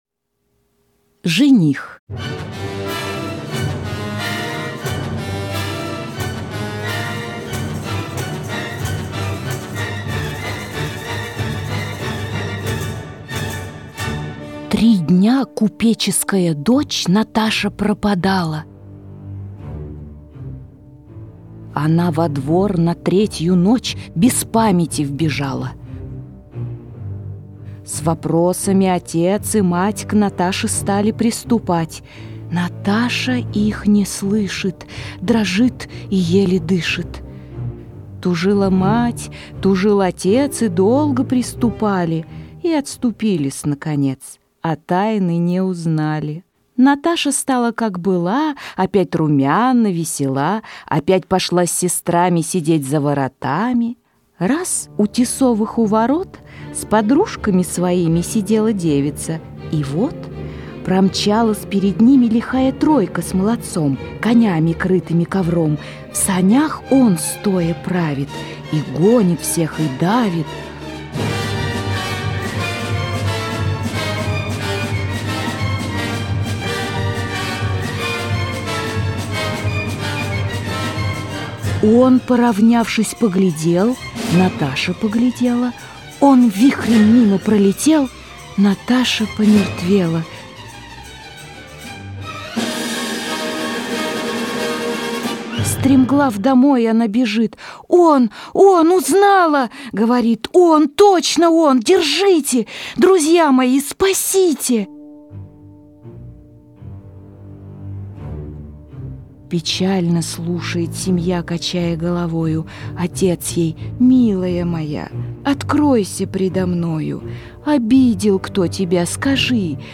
Аудиосказка «Жених»